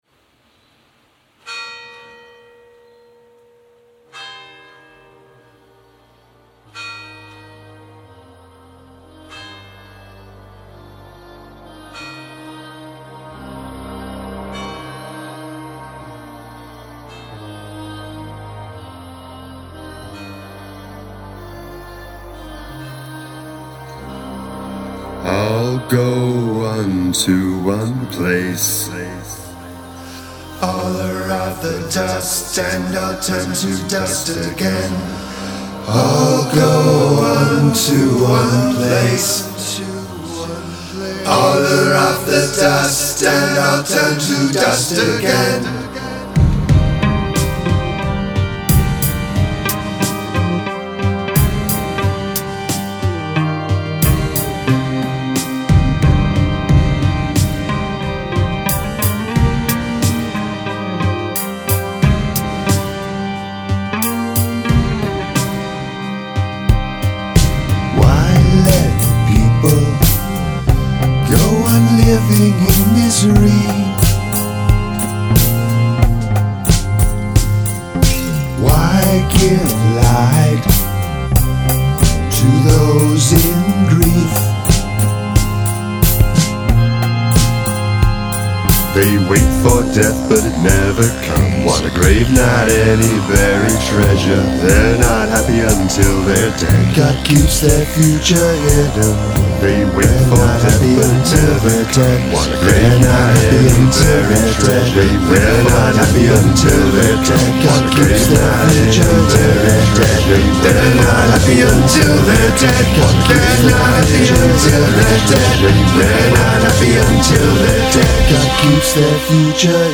Ambience
A little bit goth-y, in a good way.